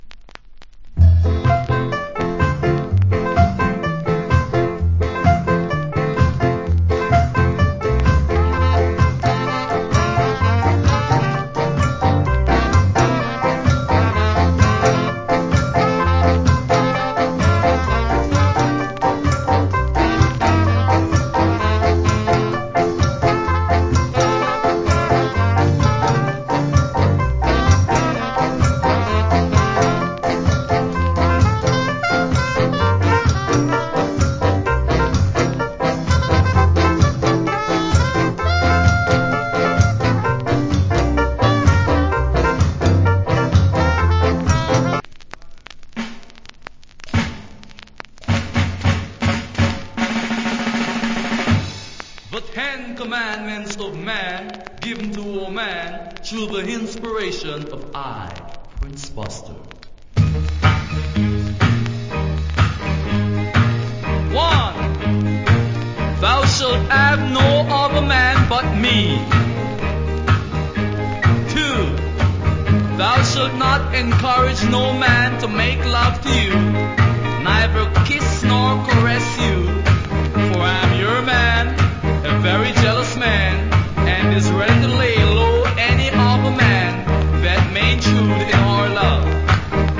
Nice Ska Inst.